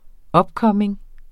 Udtale [ ˈobˌkʌmeŋ ]